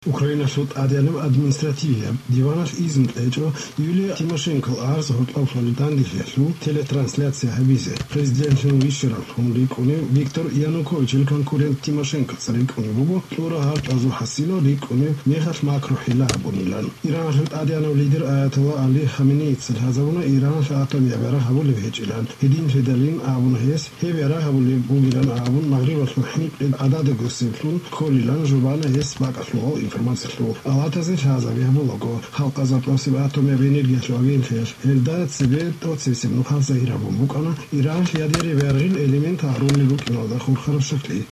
No pile-ups of consonants word-initially and what sounds like mostly word-final stress, so this isn’t Georgian or a related language… With the unusual lateral fricative that abounds in this recording, I’ll hazard a guess that this is Avar, the only widely spoken language of the Caucasus that seems to have this kind of sound, from what information I’ve been able to find online.